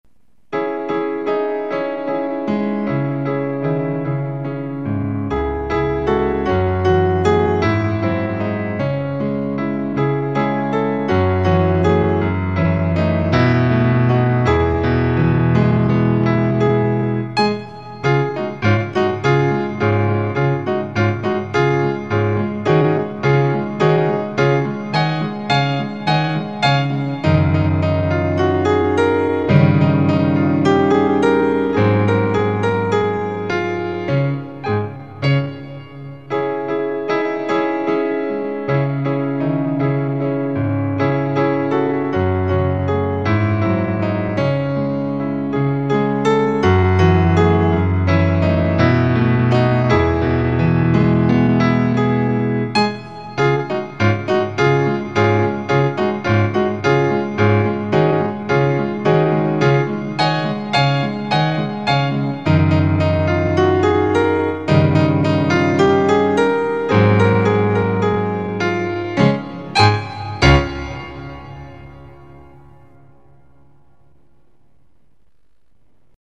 Песенка "Белочка" (минусовка"